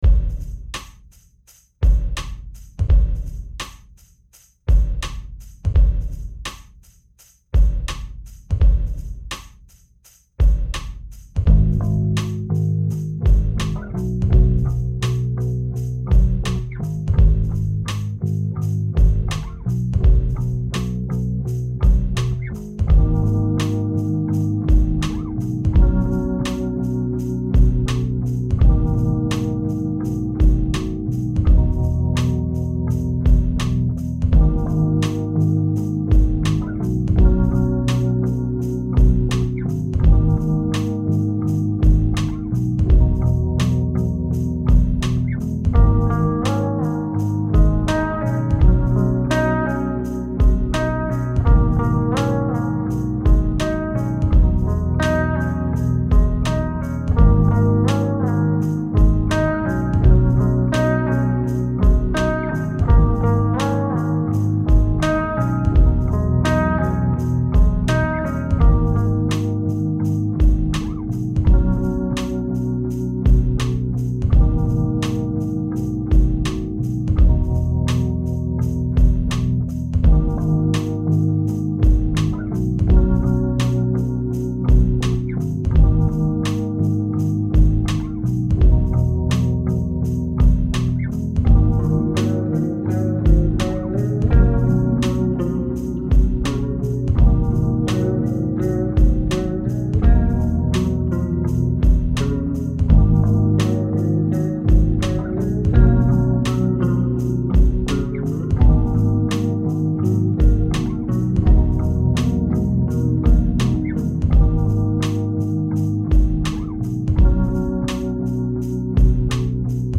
got some loops and samples that were inspired by silent hill 3 and made this.